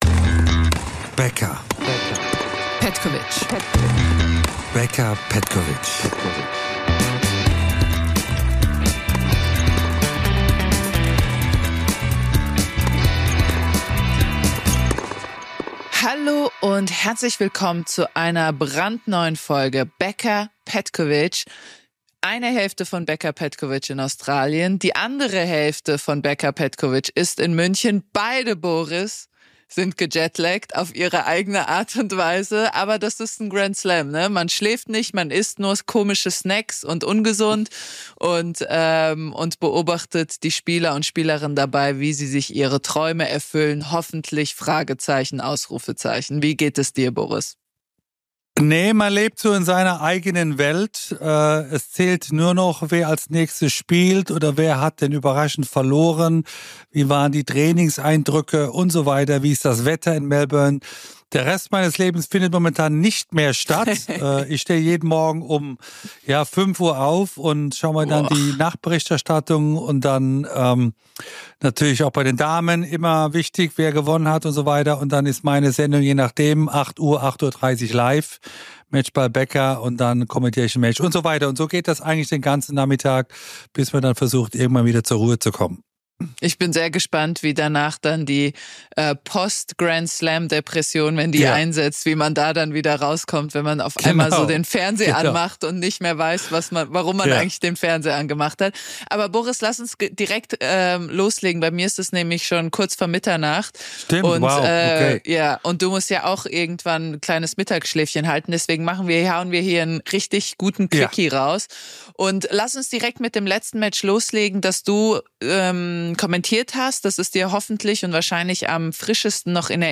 Andrea berichtet von vor Ort und Boris kommentiert für Eurosport aus München. Beiden fällt auf: Sascha Zverev spielt überragend auf und träumt vom ersten Grand Slam Sieg. Doch vorher muss er noch Carlos Alcaraz, Nummer Eins der Welt, besiegen.